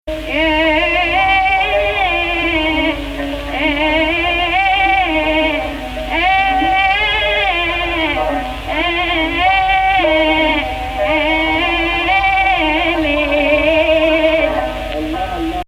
This page and the following present sentences centered on Jins Rast, the most important and central jins of Maqam Rast.